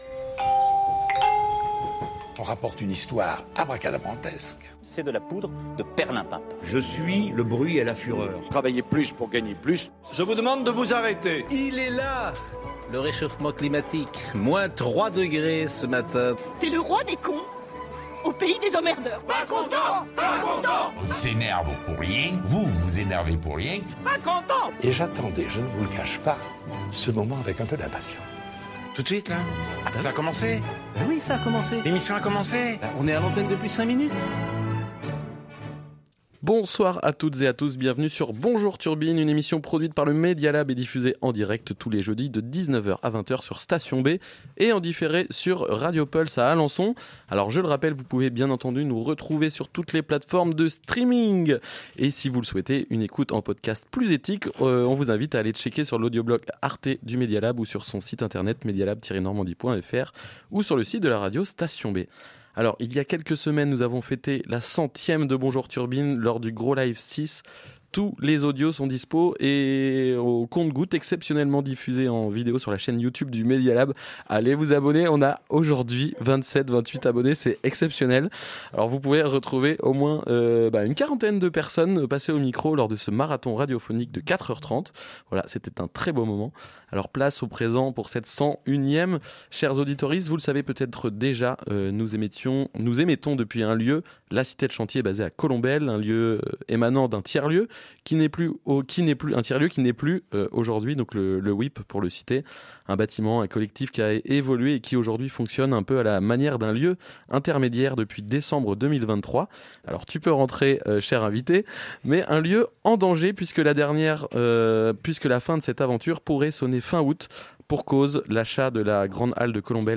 Emission enregistrée dans les studios de La cité de Chantier basée à Colombelles, un lieu émanant d’un Tiers lieux qui n’est plus aujourd’hui Le Wip.